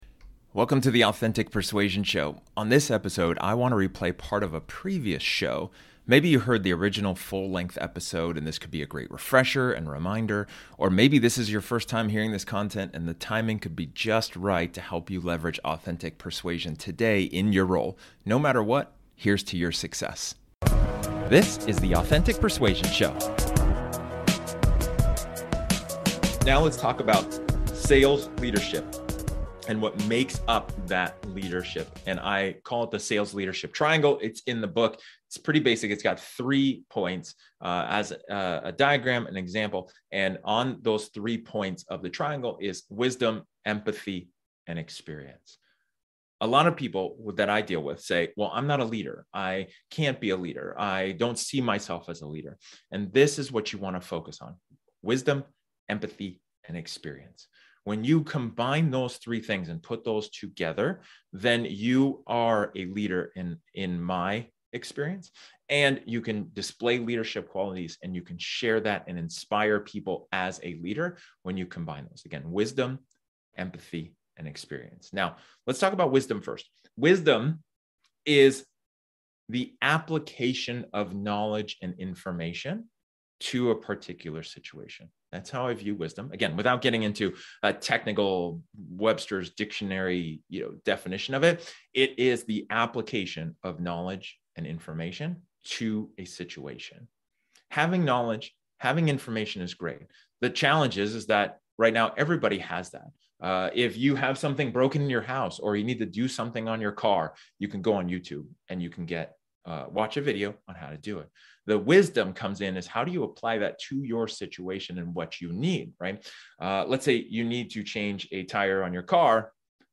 This episode is an excerpt from one of my training sessions where I talk about the question: "Are you being a Leader?"